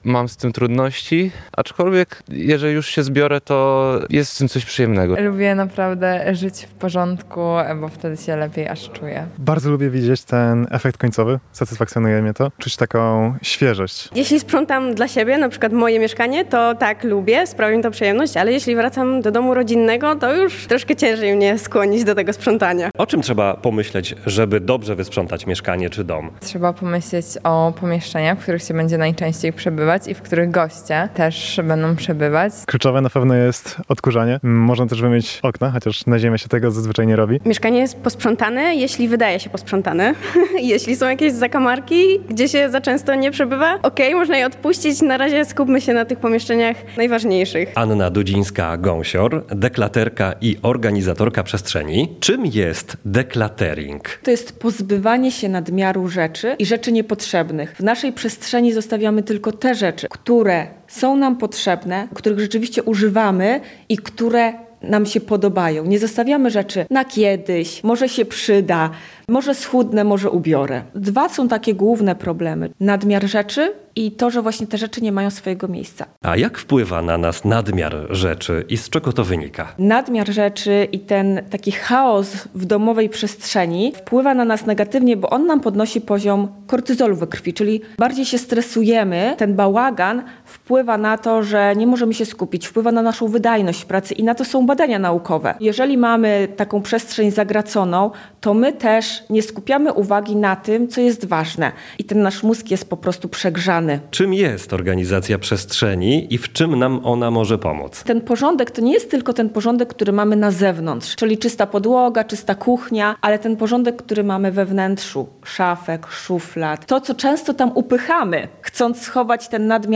Zapraszamy do wysłuchania i obejrzenia rozmowy: